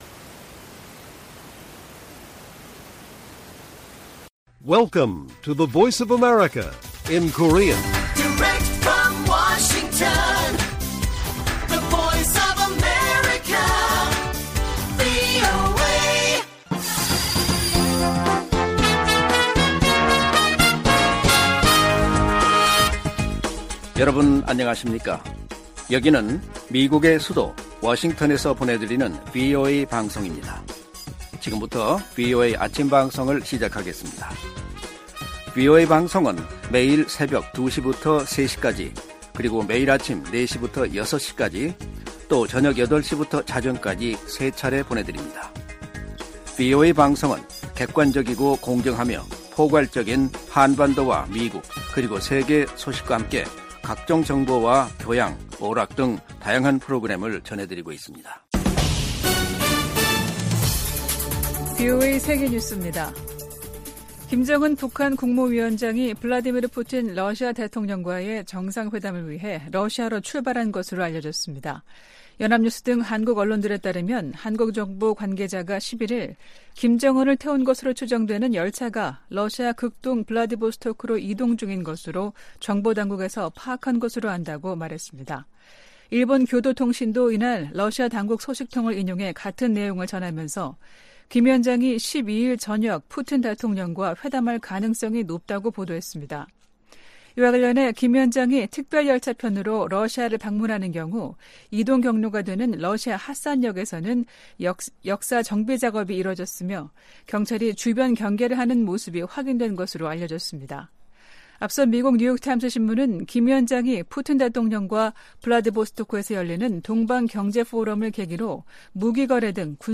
세계 뉴스와 함께 미국의 모든 것을 소개하는 '생방송 여기는 워싱턴입니다', 2023년 9월 12일 아침 방송입니다. '지구촌 오늘'에서는 8일 밤 북아프리카 모로코를 덮친 지진 피해가 커지고 있는 소식 전해드리고, '아메리카 나우'에서는 9.11 테러 22주기를 맞아 뉴욕과 펜실베이니아 등지에서 추념행사가 진행되는 이야기 살펴보겠습니다.